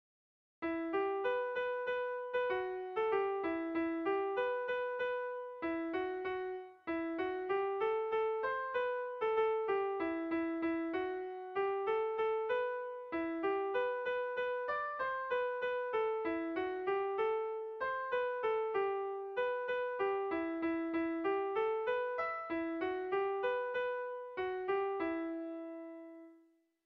Kontakizunezkoa
Zortziko handia (hg) / Lau puntuko handia (ip)
A1A2BD